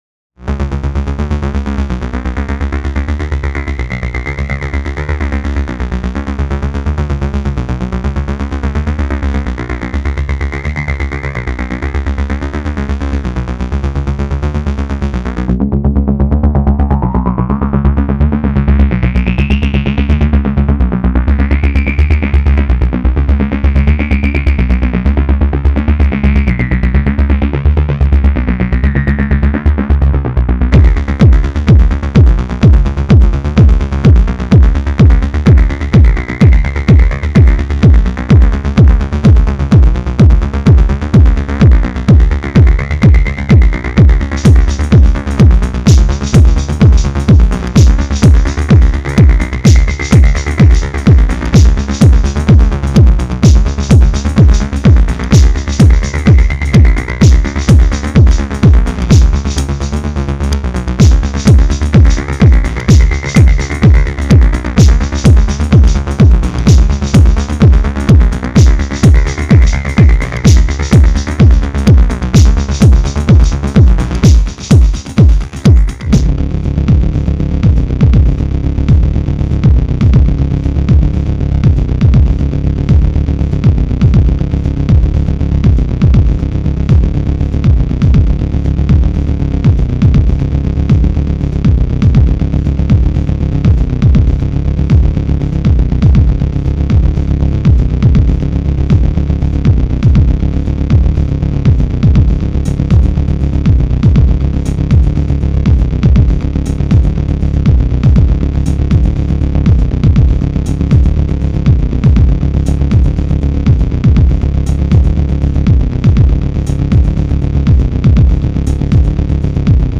power electronics
industrial techno